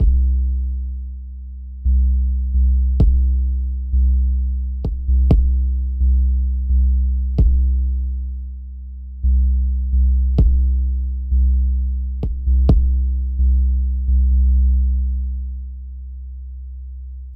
motorsport_808 WITH LIMITER.wav